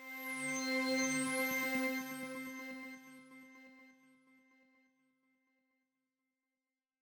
IBI Chimey C3.wav